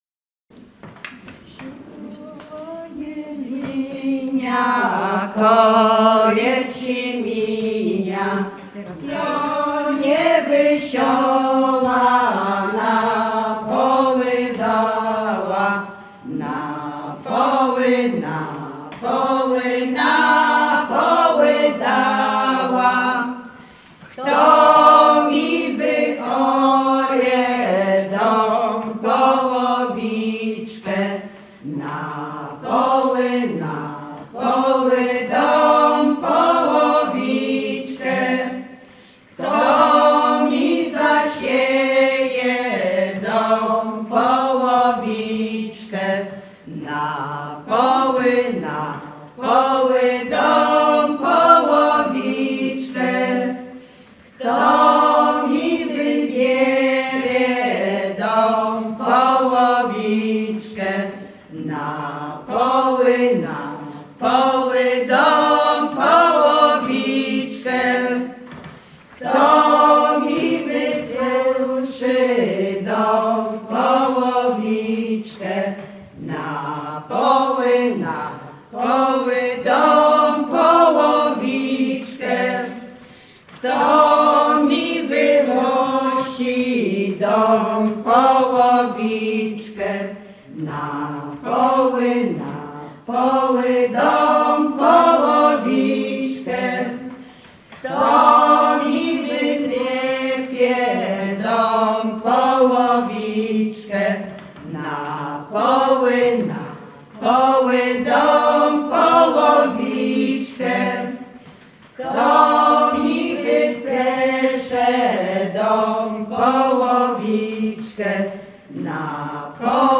Zespół Dunawiec
Ostatki
Górale Czadeccy